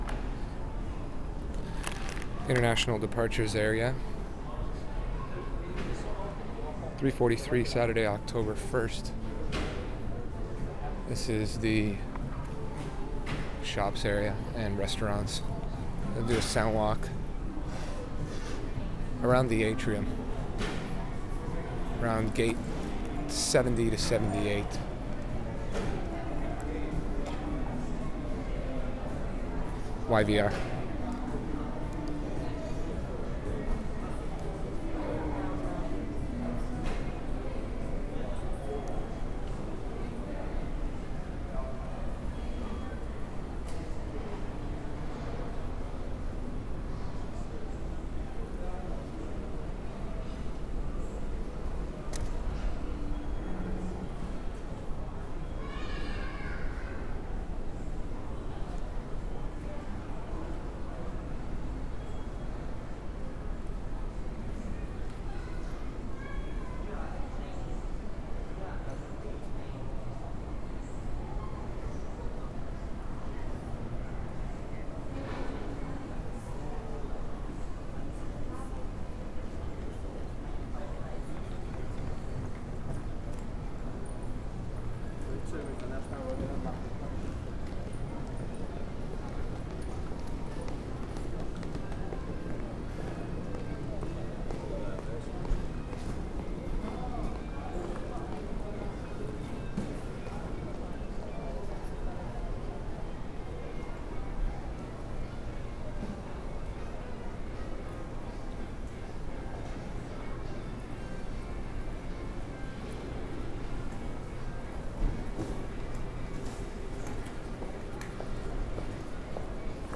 FOLDER 22 - YVR AIRPORT
Shops & Restaurants area Soundwalk - Gates 70 - 78, 3:40
2. Track ID @ intro, Soundwalk around atrium, child crying at 0:55, conversation at 1:20, footsteps and restaurant music at 1:35, footsteps - space opens up at about 2:00, track ID @ 2:58 "by the fish tank", loud voice at 3:10, track ID at 3:38 - end - "back to starting point".